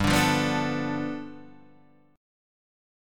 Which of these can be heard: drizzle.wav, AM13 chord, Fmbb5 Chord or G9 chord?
G9 chord